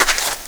SAND 3.WAV